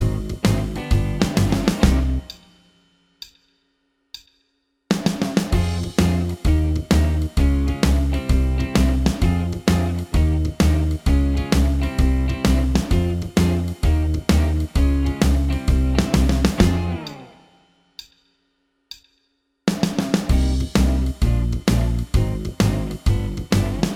Minus Guitars Rock 'n' Roll 3:21 Buy £1.50